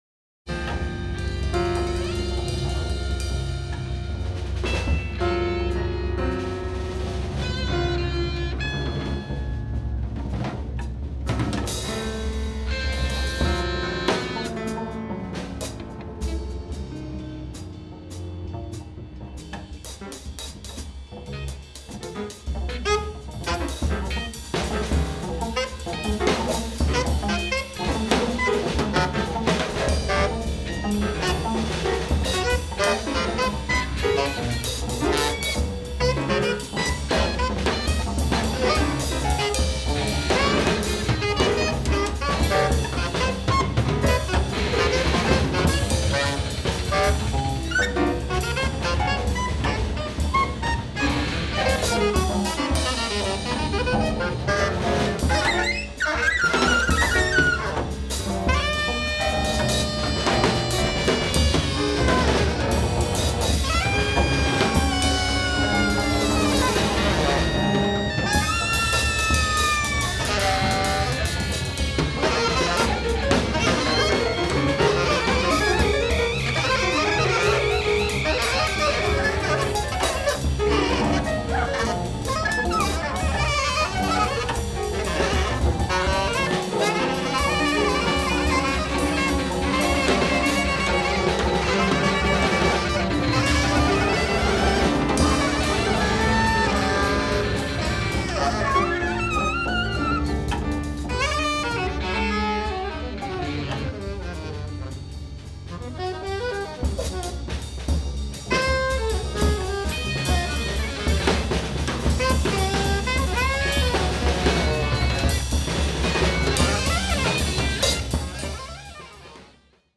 Volume 1, Cafe OTO 9th July 2022